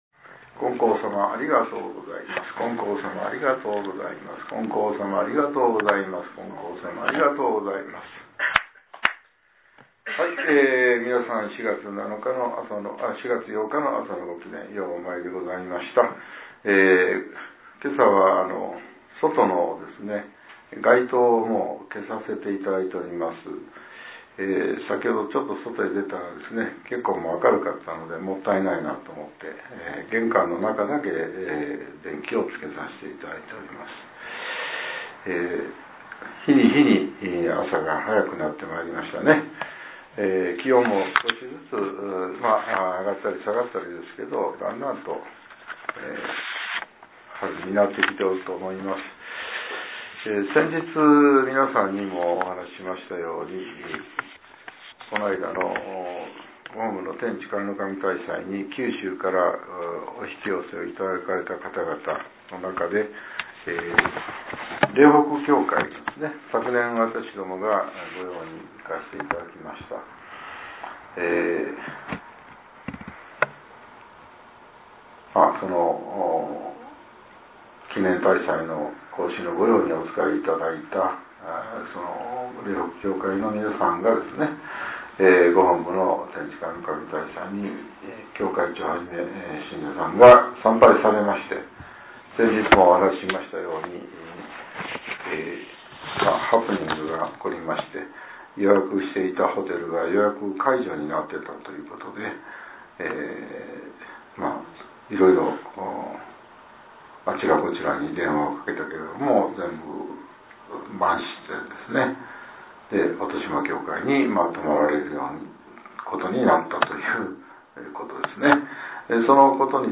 令和７年４月８日（朝）のお話が、音声ブログとして更新されています。